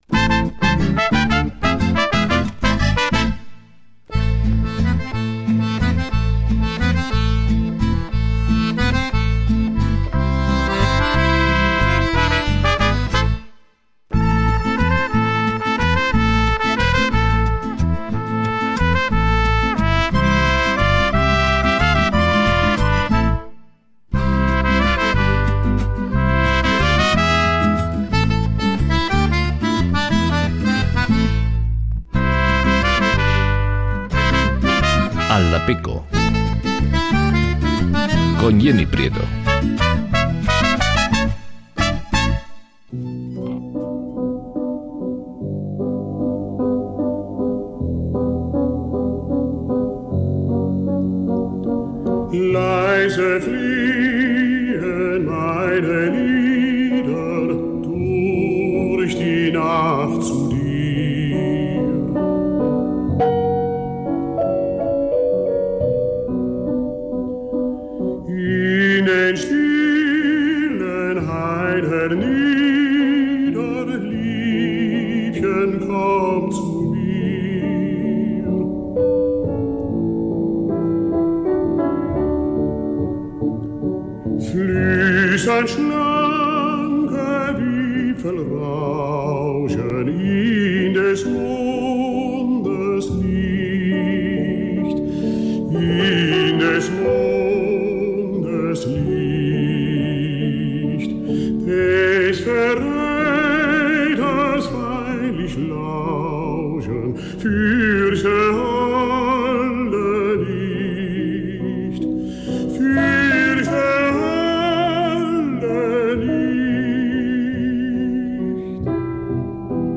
Múisca y entrevistas para la sobremesa